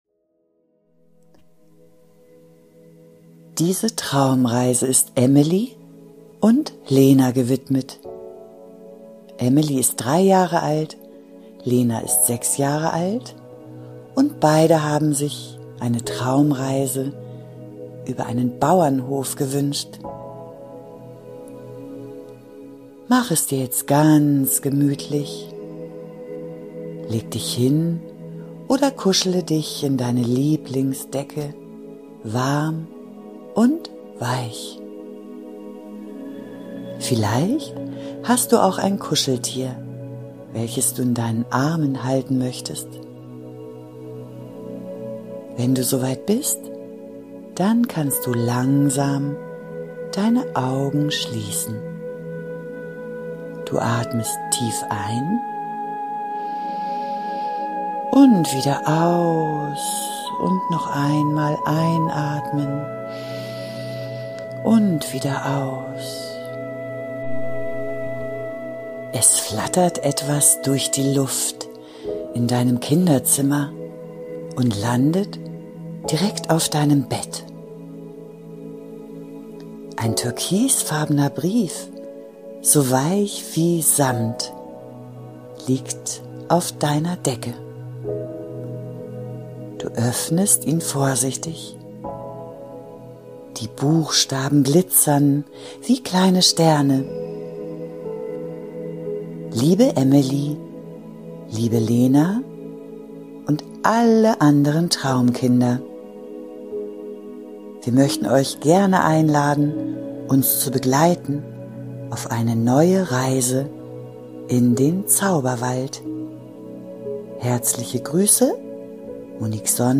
Eine liebevoll-märchenhafte Traumreise